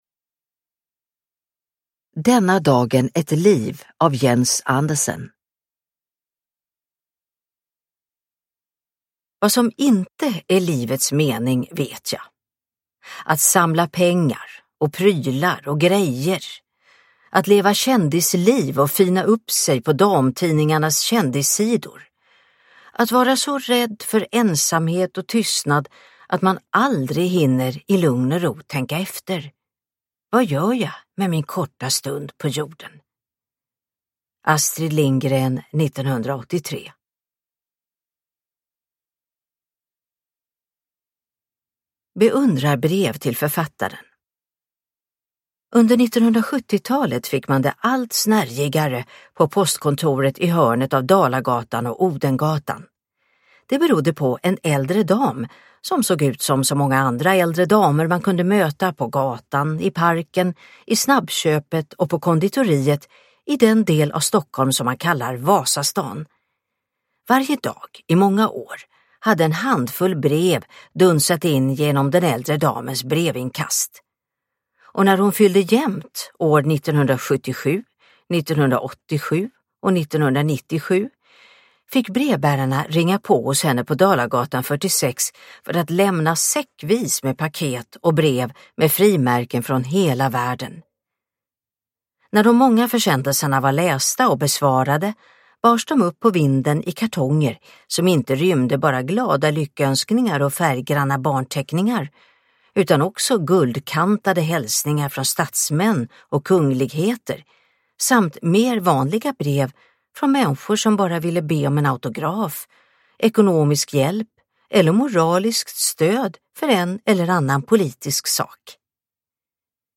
Denna dagen, ett liv : en biografi över Astrid Lindgren – Ljudbok – Laddas ner